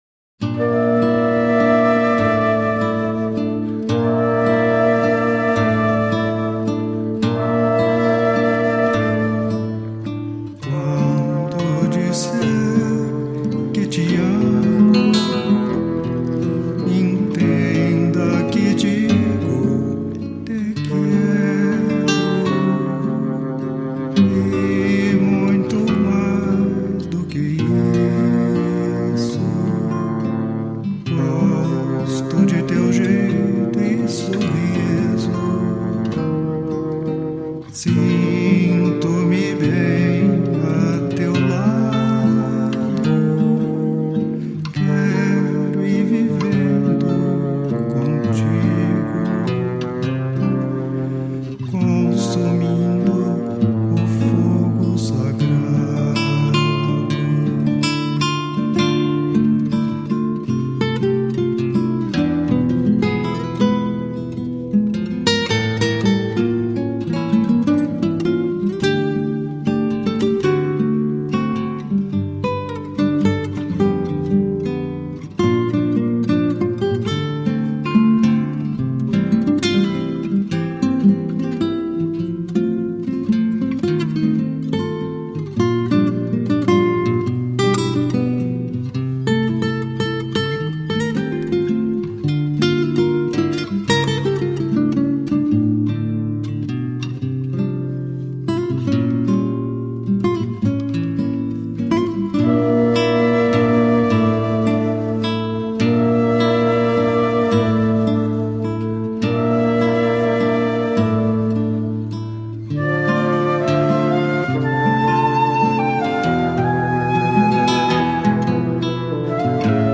Brazilian contemporary and traditional flute.
with flute, acoustic guitar, fagot and percussion